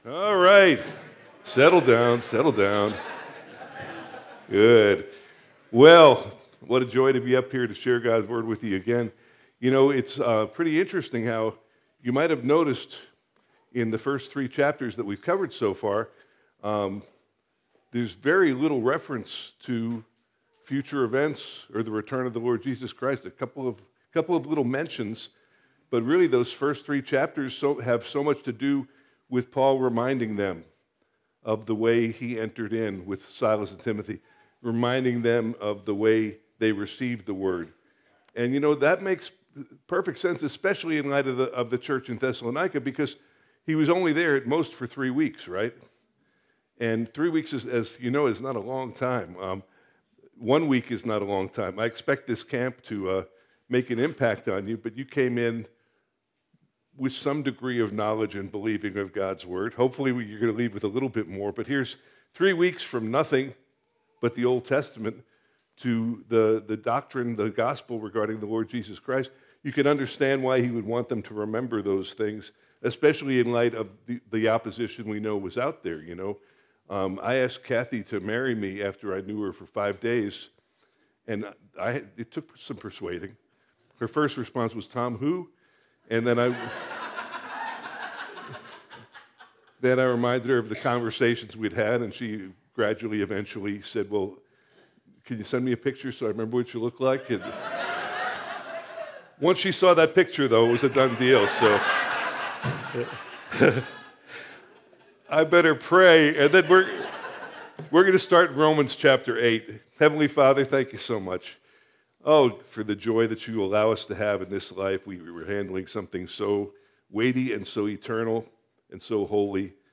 Family Camp 2024